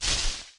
grass.ogg